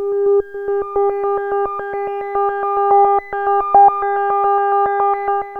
JUP 8 G5 11.wav